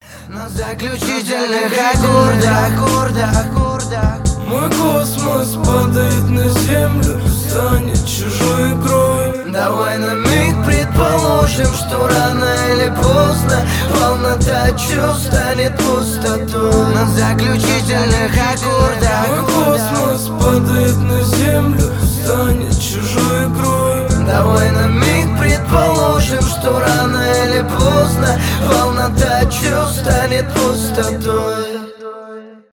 лирика , рэп , грустные